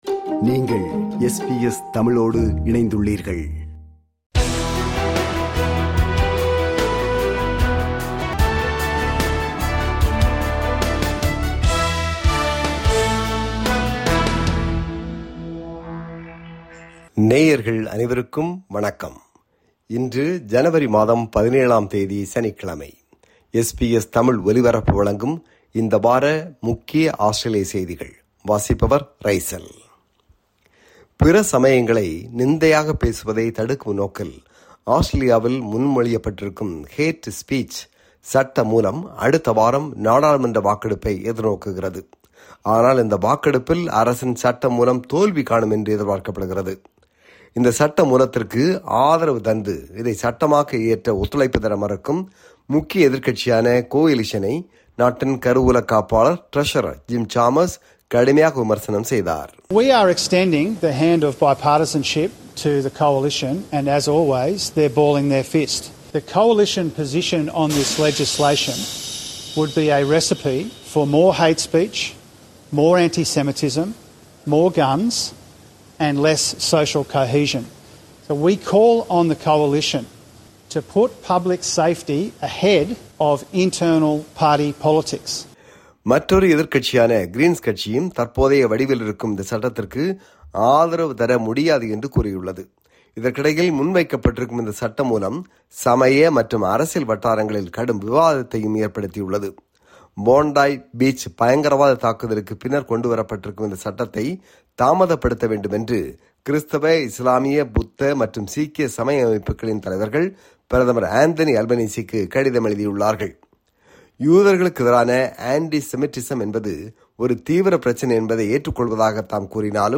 ஆஸ்திரேலியாவில் இந்த வாரம் (10 – 17 ஜனவரி 2026) நடந்த முக்கிய செய்திகளின் தொகுப்பு.